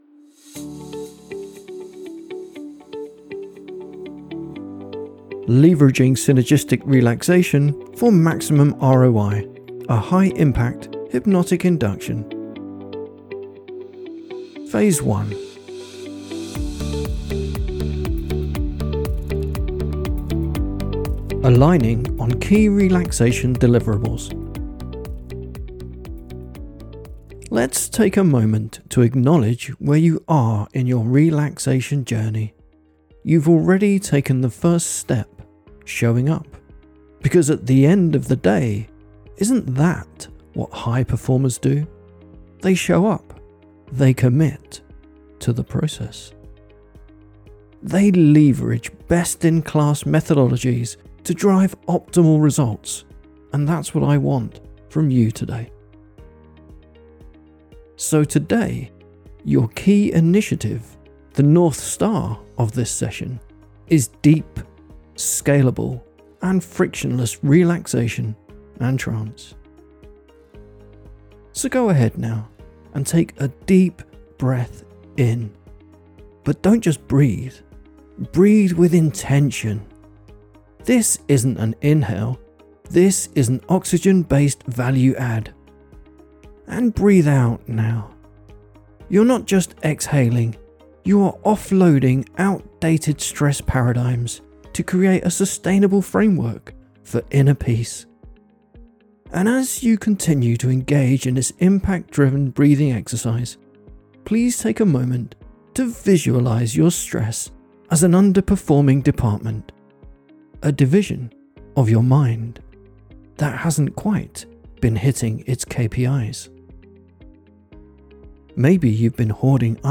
In this immersive hypnotic session, you will systematically streamline cognitive workflows, offload unnecessary tension, and leverage subconscious automation to unlock a frictionless flow state.
— Hypnosis induction file, full of LinkedIn bullshit, released for April fools 2025.